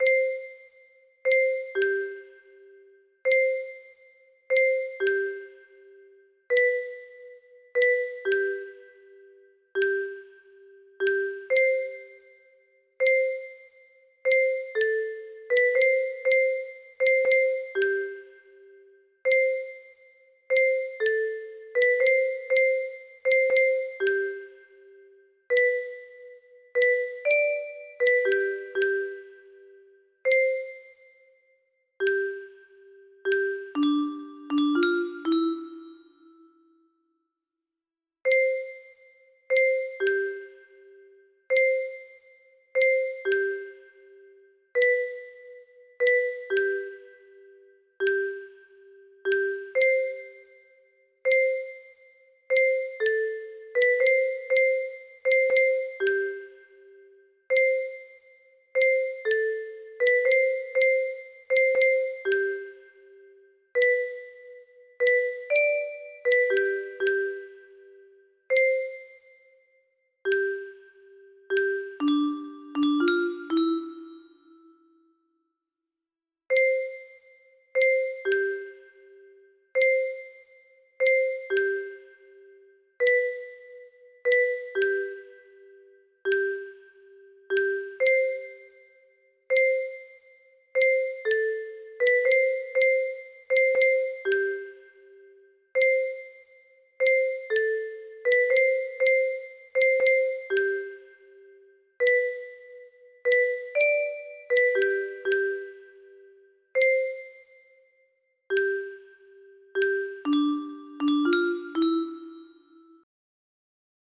Mohr and Gruber - Silent night - Alto music box melody
Wow! It seems like this melody can be played offline on a 15 note paper strip music box!